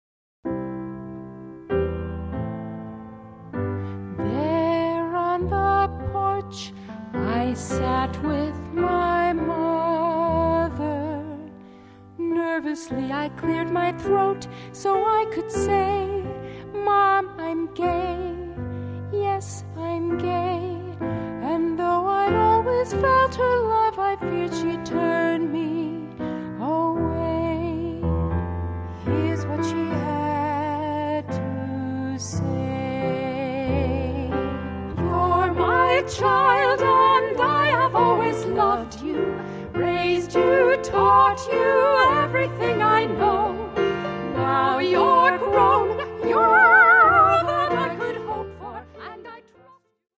--comedy music parody